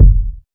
KICK.65.NEPT.wav